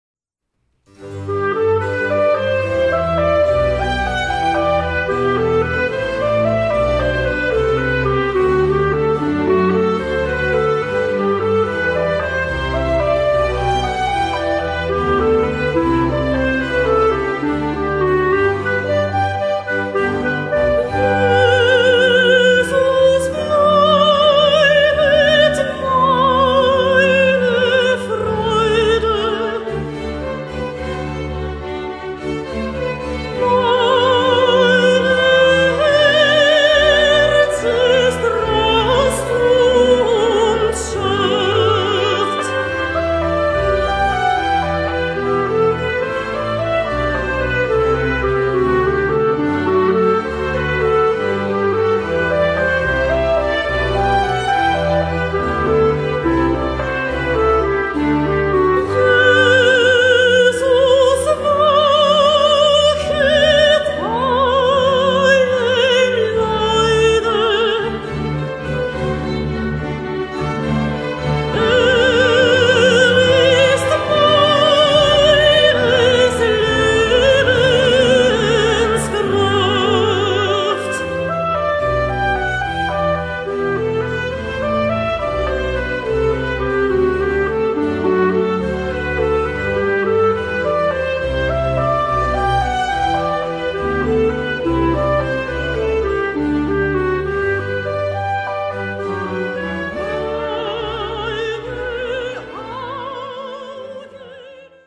Voicing: Soprano, Clarinet and Orchestra